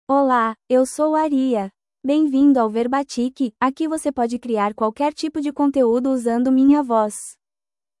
AriaFemale Portuguese AI voice
Aria is a female AI voice for Portuguese (Brazil).
Voice sample
Listen to Aria's female Portuguese voice.